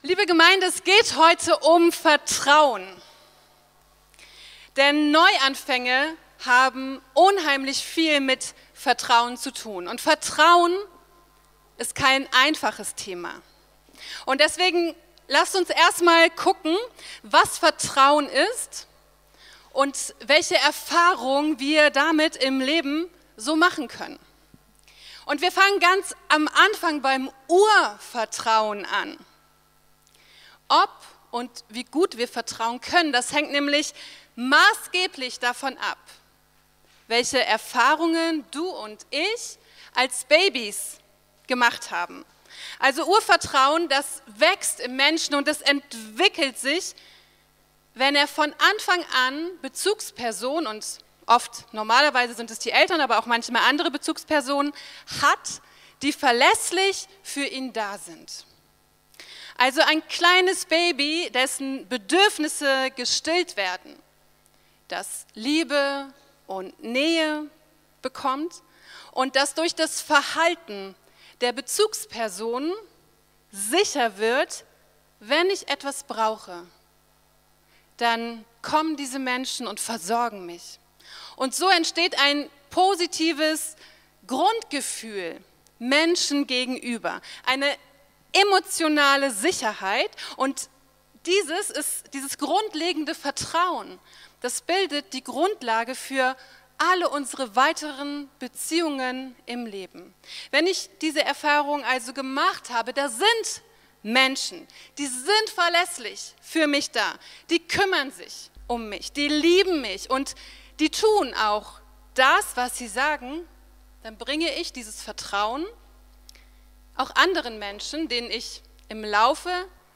04-Predigt-9.mp3